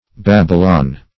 BABYLON - definition of BABYLON - synonyms, pronunciation, spelling from Free Dictionary